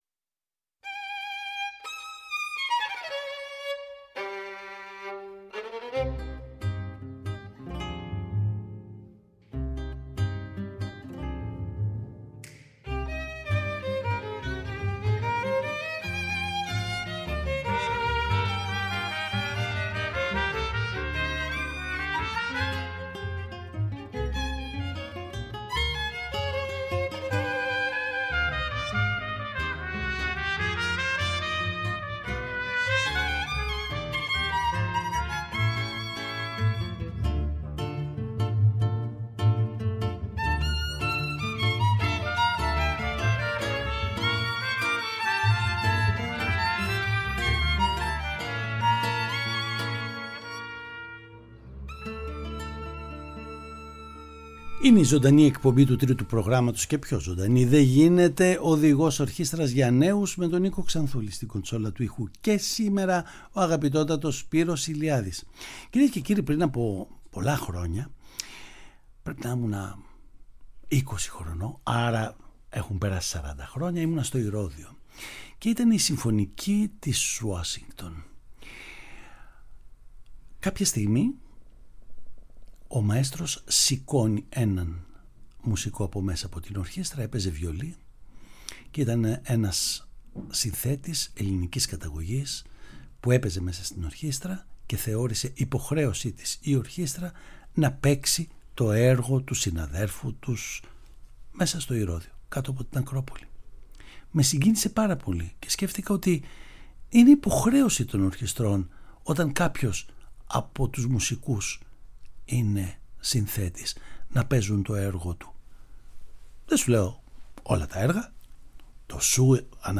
Παραγωγή-Παρουσίαση: Νίκος Ξανθούλης